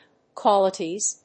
/ˈkwɑlʌtiz(米国英語), ˈkwɑ:lʌti:z(英国英語)/